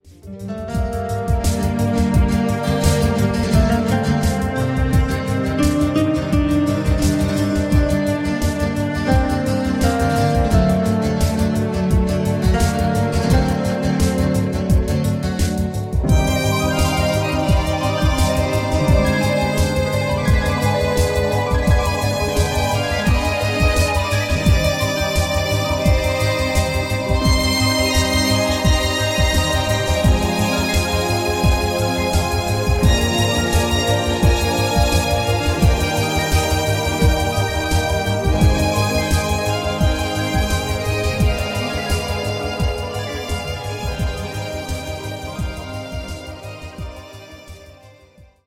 CHILLOUT LOUNGE MUSIC